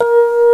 RHODES4.WAV